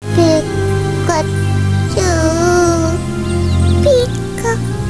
pikasad.wav